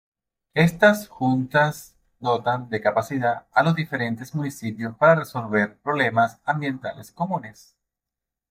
Pronounced as (IPA) /resolˈbeɾ/